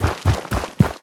biter-walk-big-6.ogg